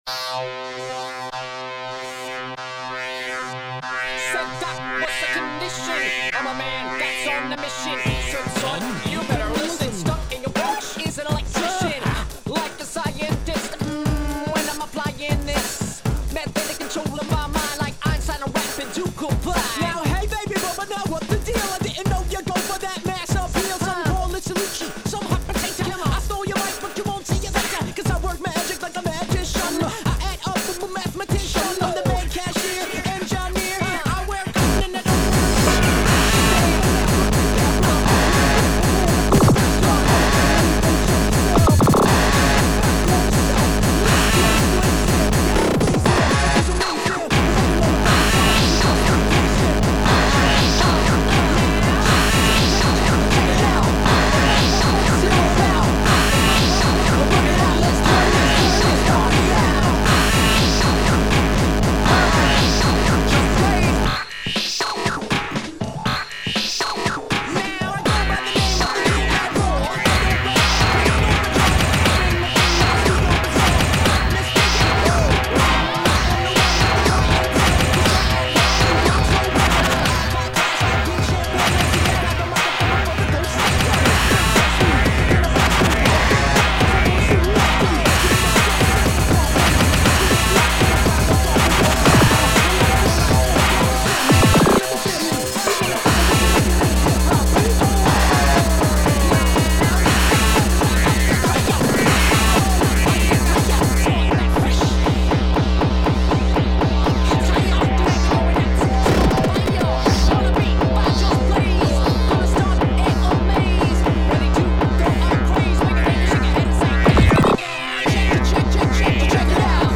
le son est globalement assez sourd.
Le OH OH AH AH AH est effectivement très marrant :)
Hmm ça s'améliore, ça galope et c'est plus prenant.